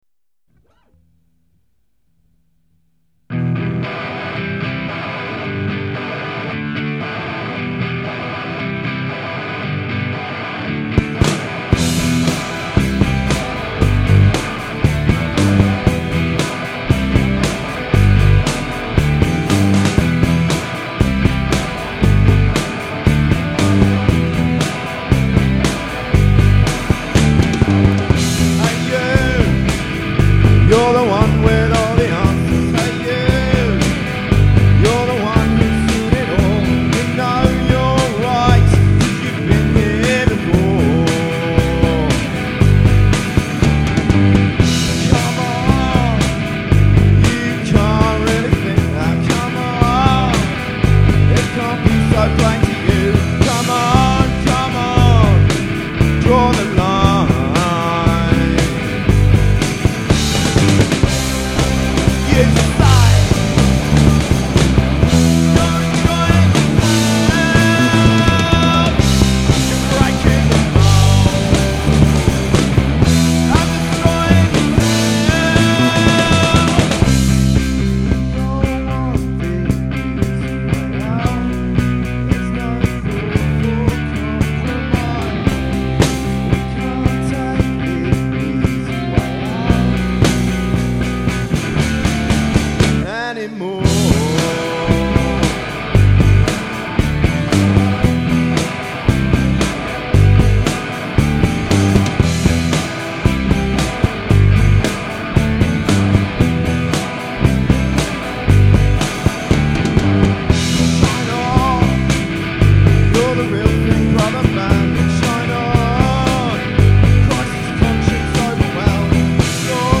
1-5 diy recordings mushroom rehearsal studios